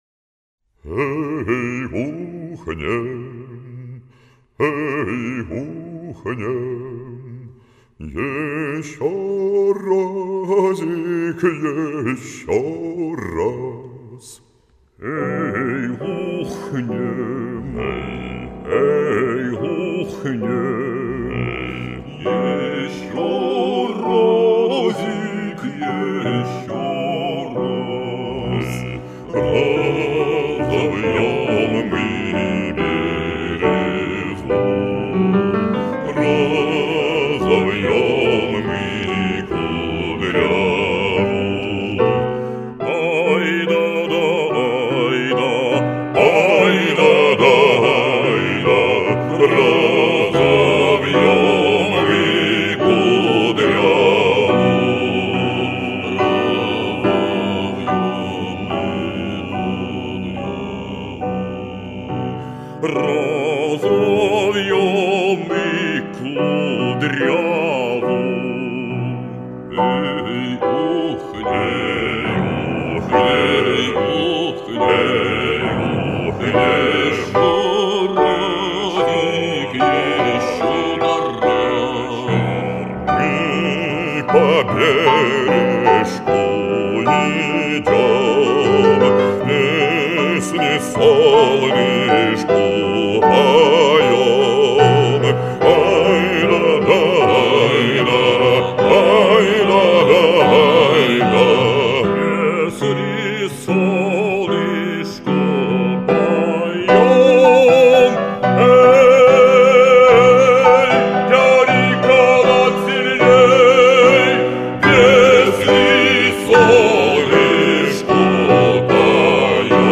Русская-народная-Э-э-эй-ухнем_mp3-flamingo.ru_.mp3